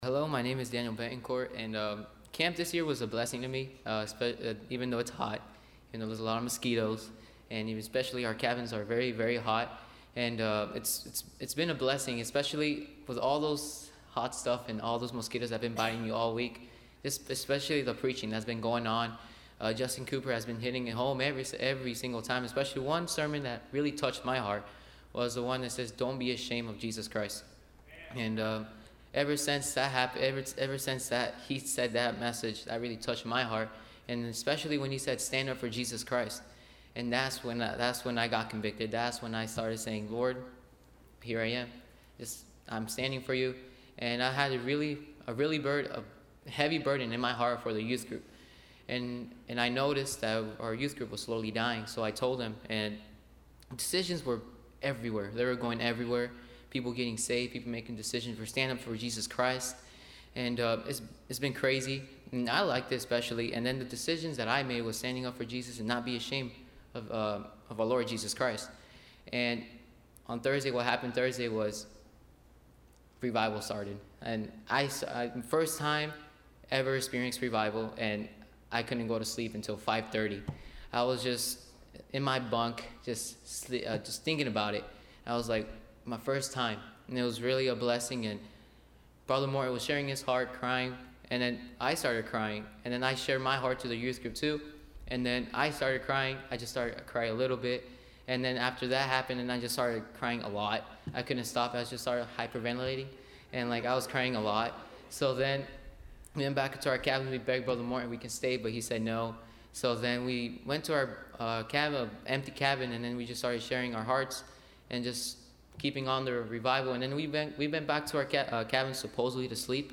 Teen Camp Testamonies / When was the Last Time you Saw Revival? – Landmark Baptist Church
Service Type: Wednesday Teens